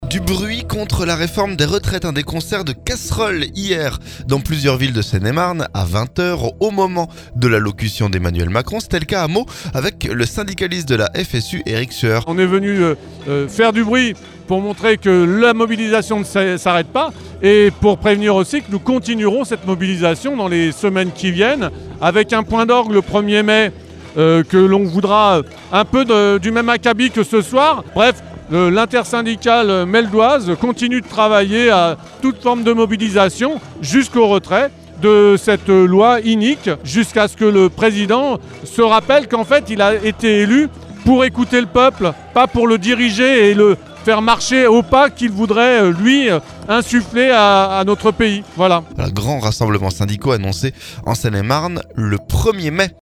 MEAUX - Concert de casseroles pendant l'allocution d'Emmanuel Macron
Du bruit contre la réforme des retraites ! Des concerts de casserole lundi dans plusieurs villes de Seine-et-Marne, à 20h... Au moment de l'allocution d'Emmanuel Macron.